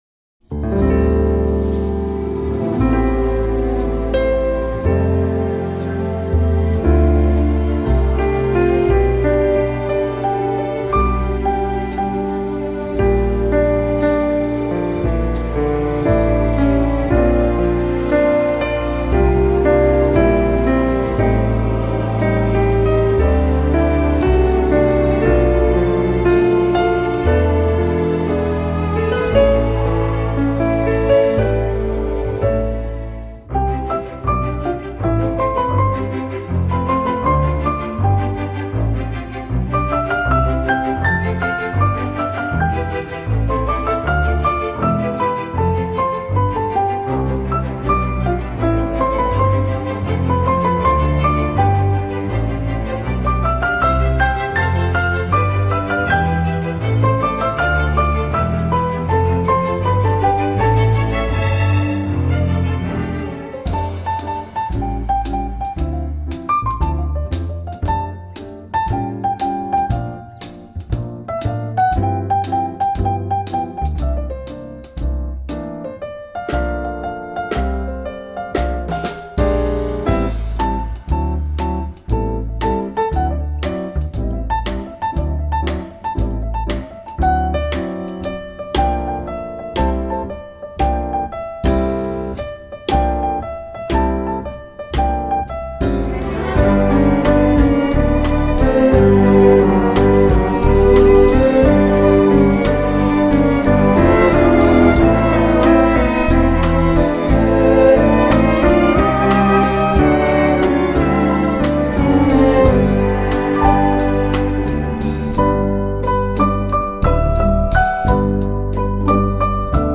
piano
contrabas / basgitaar
drums / vibrafoon / percussie